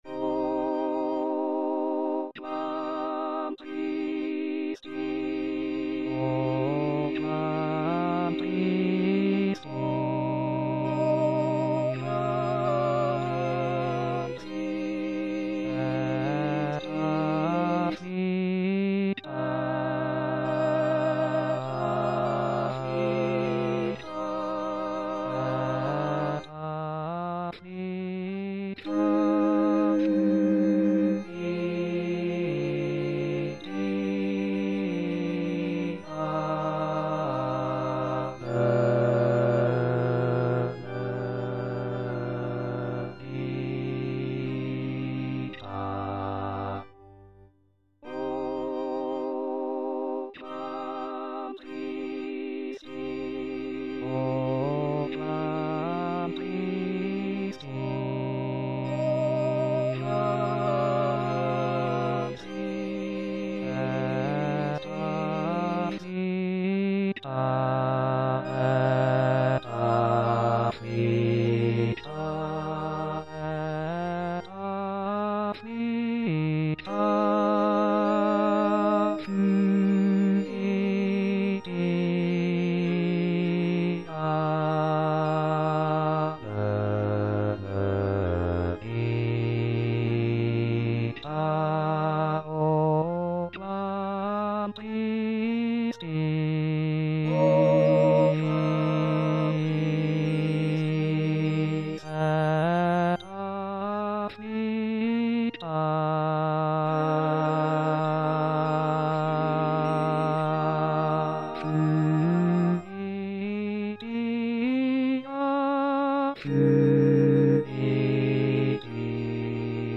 Parole 3: Mulier, ecce filius tuus        Prononciation gallicane (à la française)